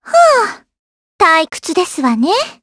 Aisha-Vox_Victory_jp.wav